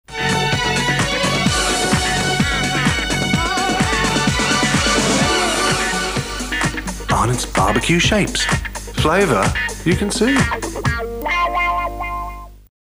There is another version of the ad